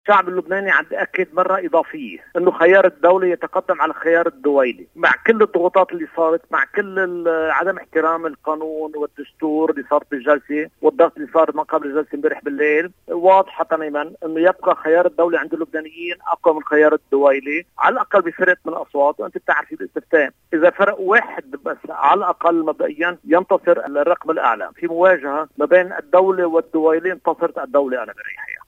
شدد النائب أشرف ريفي أن جلسة اليوم تؤكد مرة إضافية ان خيار الدولة يتقدم على خيار الدويلة مع كل الضغوطات التي مورست ومع عدم احترام القانون والدستور إلى جانب الضغط الواضح الذي مورس عشية الجلسة أيضاً. ريفي وفي حديث خاص للبنان الحر اعتبر أن خيار الدولة يبقى عند اللبنانيين أكبر من خيار الدويلة، على الأقل […]